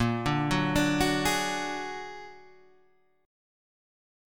Bb+7 chord